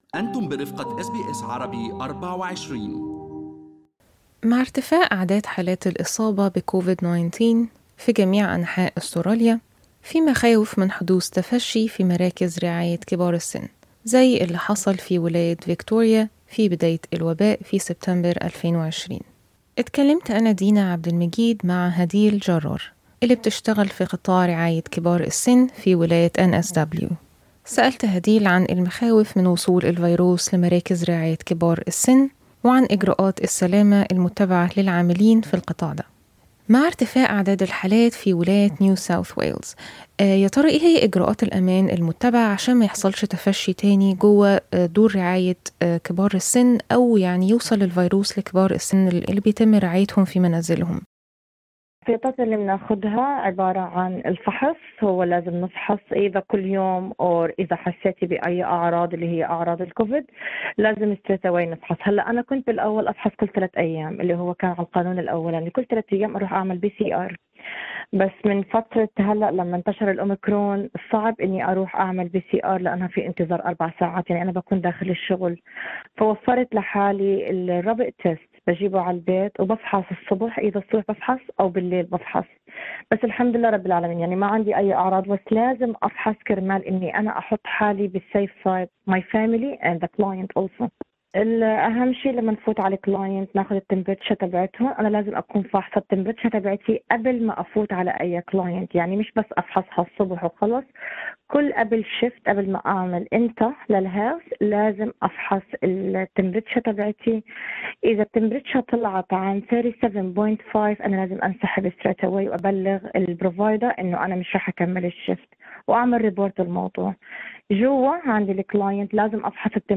"أخاف كثيرا على أسرتي": عاملة في رعاية المسنين تحكي عن اجراءات السلامة ومخاوف العاملين في القطاع